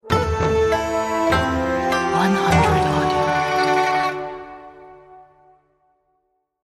This is a musical sound effect, which is suitable for the game failure scene of antique theme. 这是一首音乐类音效，适合古风题材的游戏失败场景。